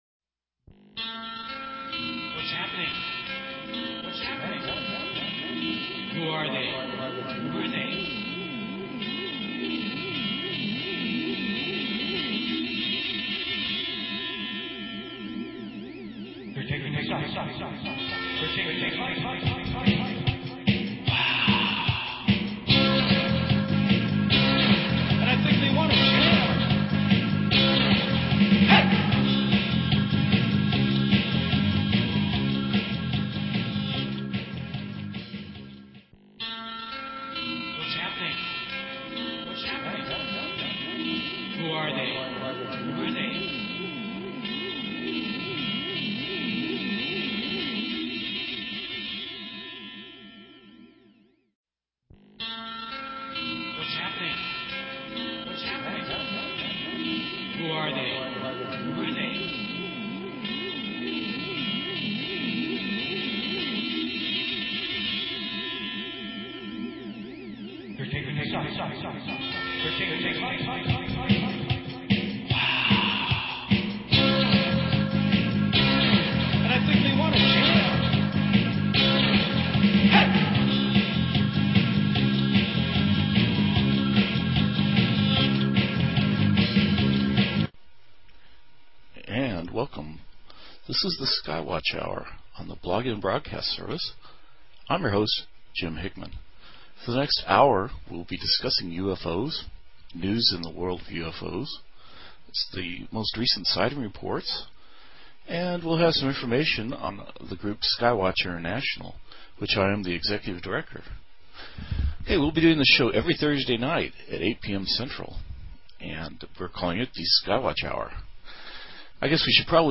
Talk Show Episode, Audio Podcast, Skywatch_Hour and Courtesy of BBS Radio on , show guests , about , categorized as